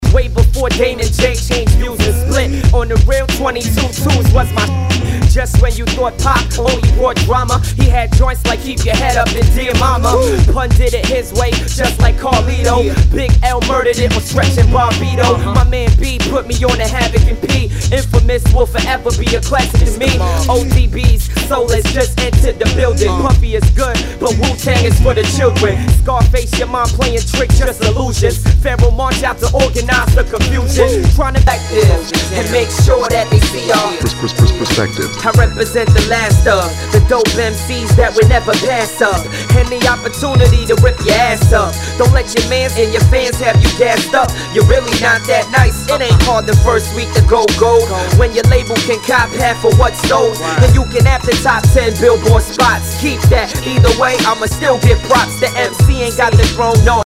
HIPHOP/R&B